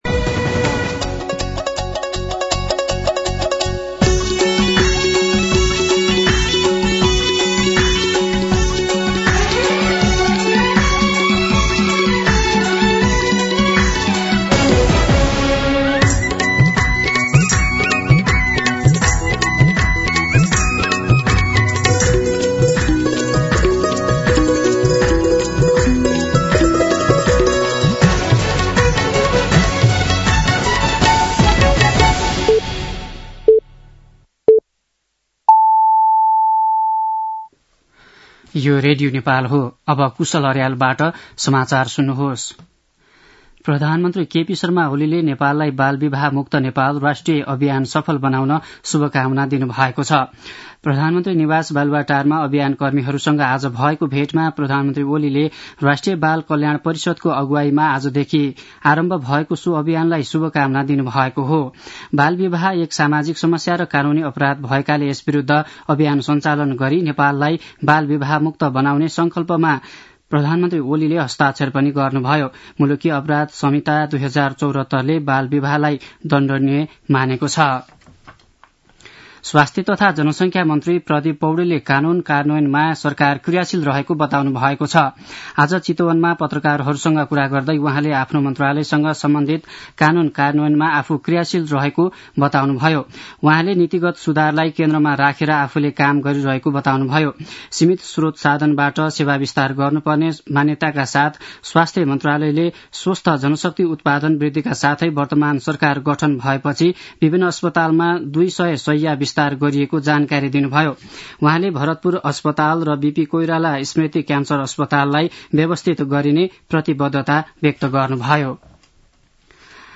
दिउँसो ४ बजेको नेपाली समाचार : १७ पुष , २०८१
4pm-Nepali-News-.mp3